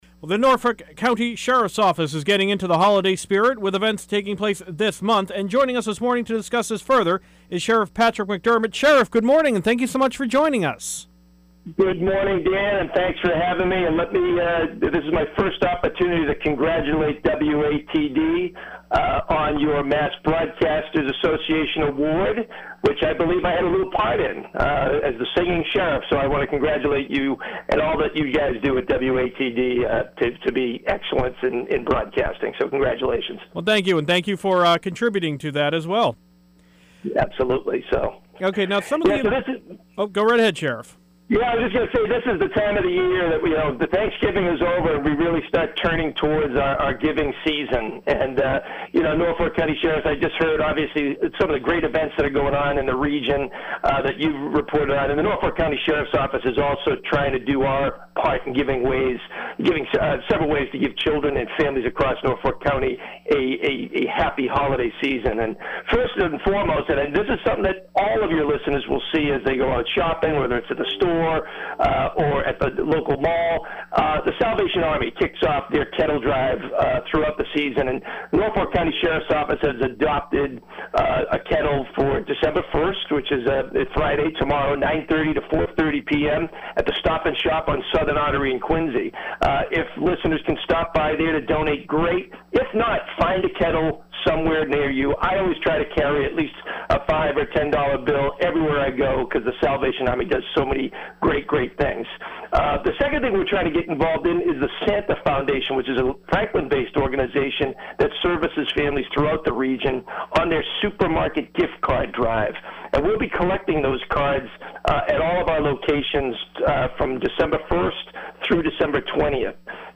Norfolk County Sheriff Patrick McDermott speaks